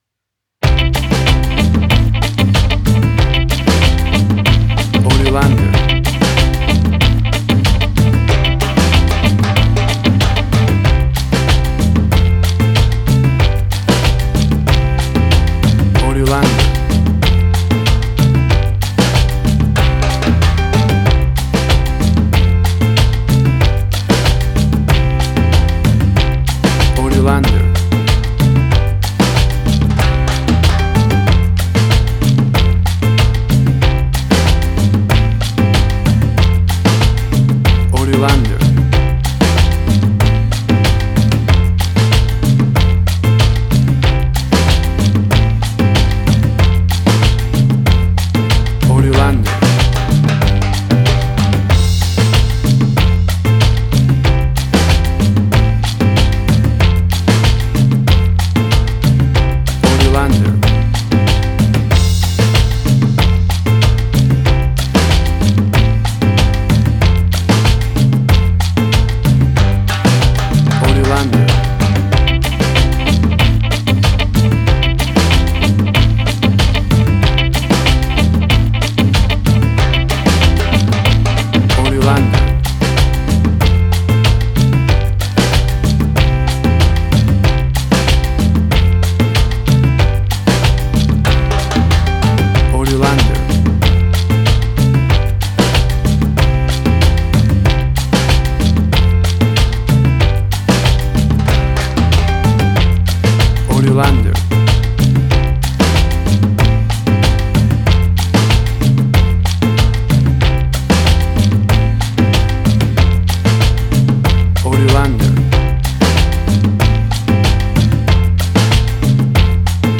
A fun and upbeat bouncy calypso island beat.
That perfect carribean calypso sound!
Tempo (BPM): 94